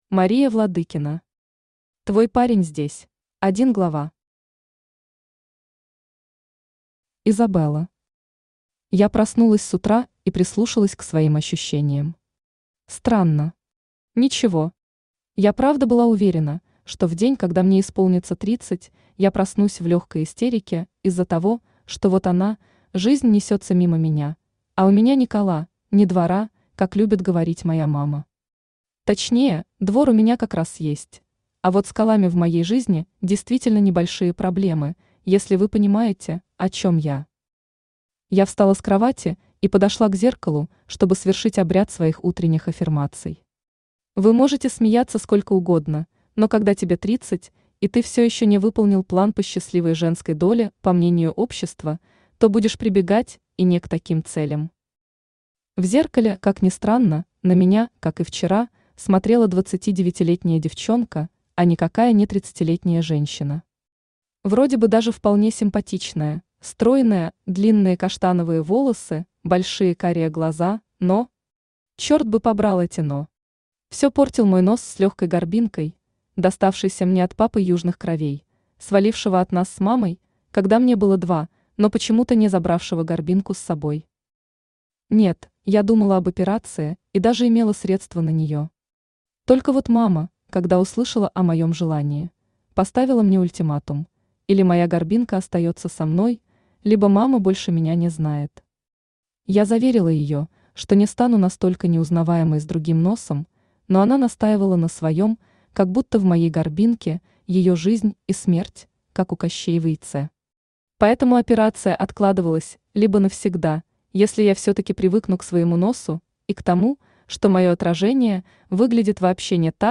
Аудиокнига Твой парень здесь | Библиотека аудиокниг
Aудиокнига Твой парень здесь Автор Мария Андреевна Владыкина Читает аудиокнигу Авточтец ЛитРес.